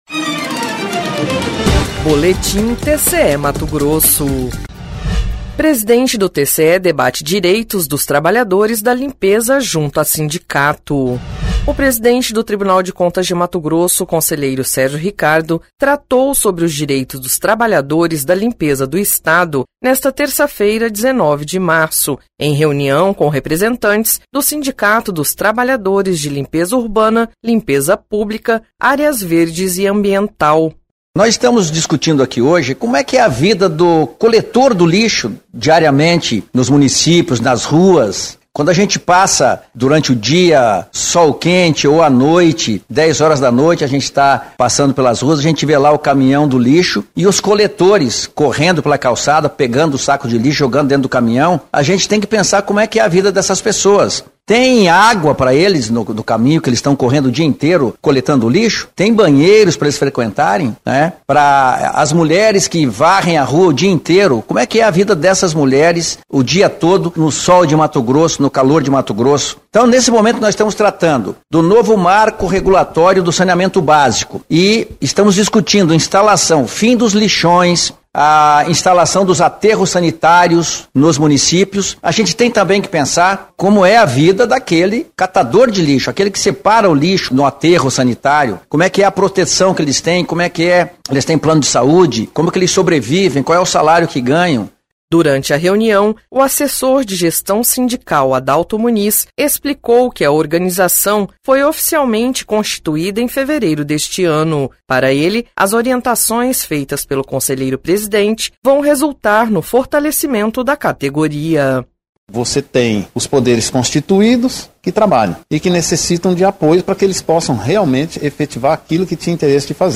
Sonora: Sérgio Ricardo – conselheiro presidente do TCE-MT